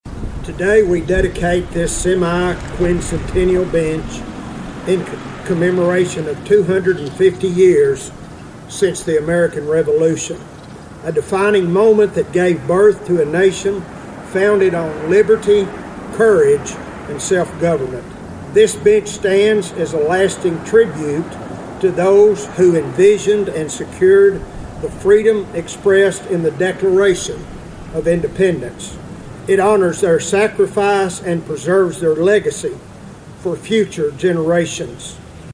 The ceremony was held on the front lawn of the Caldwell County Courthouse with a good attendance of residents.